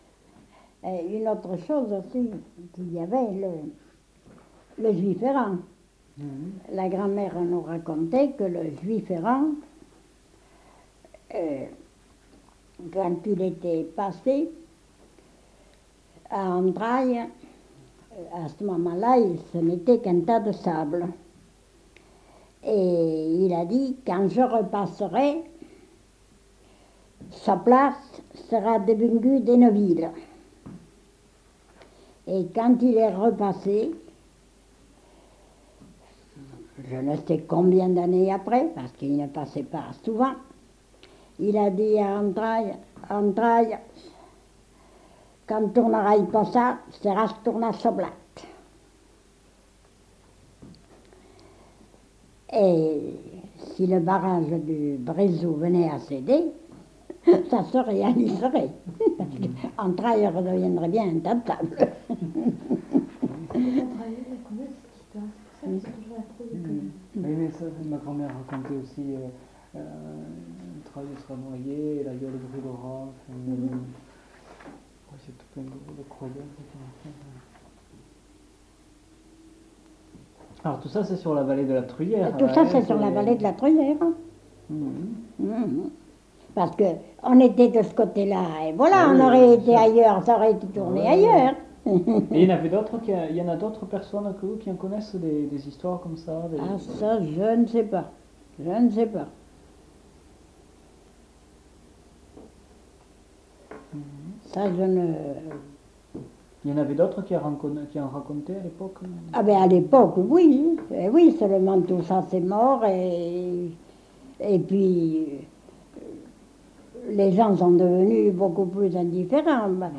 Lieu : Tournay
Genre : conte-légende-récit
Effectif : 1
Type de voix : voix de femme
Production du son : parlé
Classification : récit légendaire